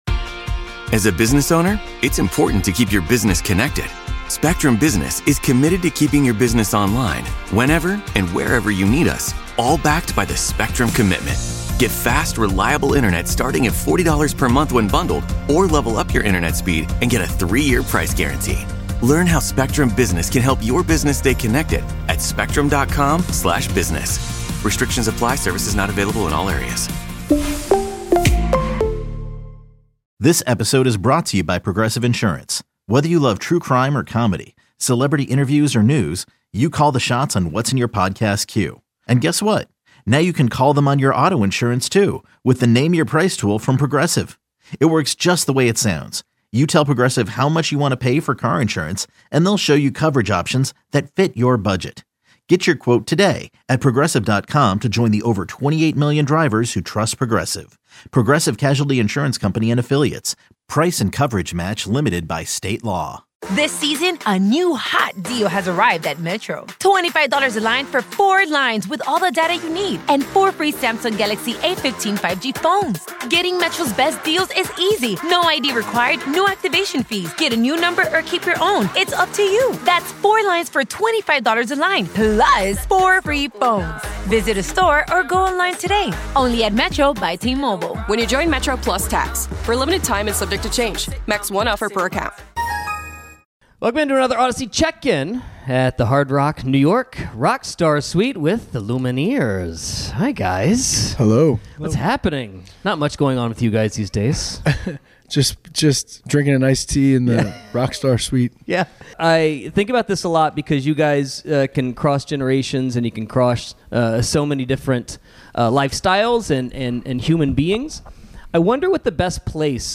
Jeremiah Fraites and Wesley Schultz of The Lumineers sat down
for an Audacy Check In from the Hard Rock New York Rockstar Suite